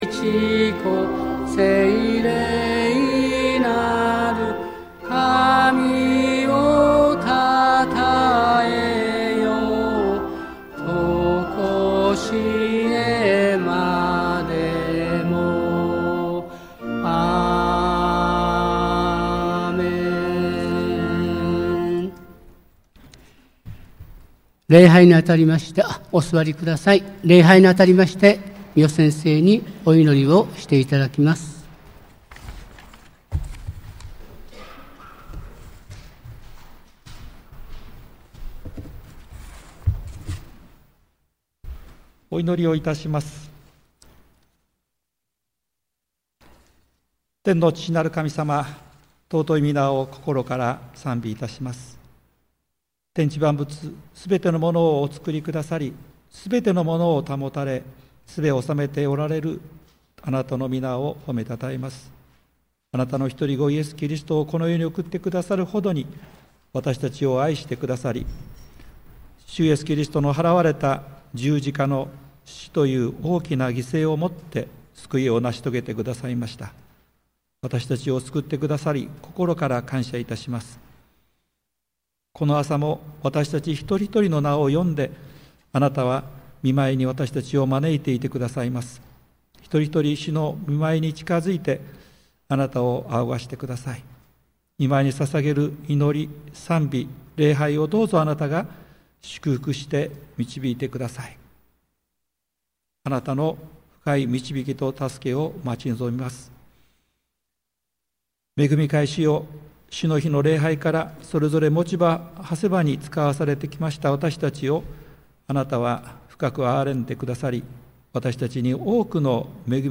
2024年12月15日 日曜礼拝（音声）